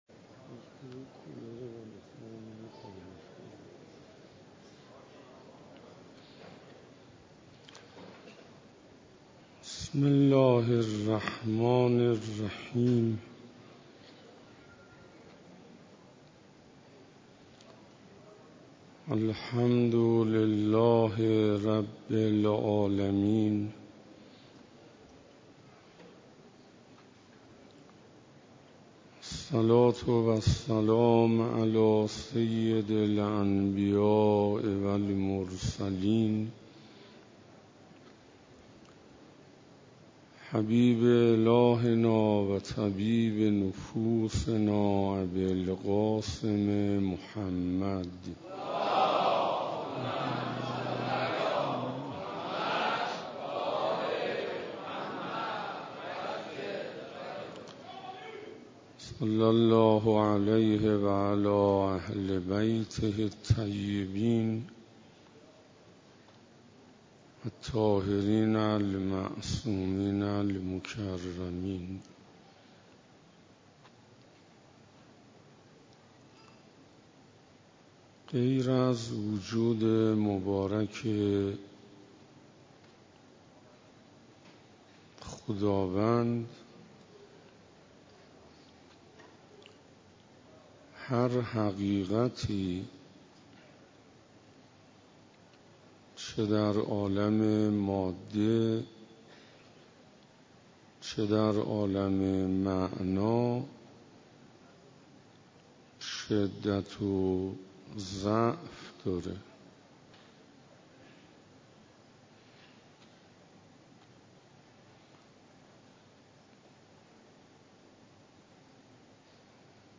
روز13 رمضان97 - مسجد امیر علیه السلام - رمضان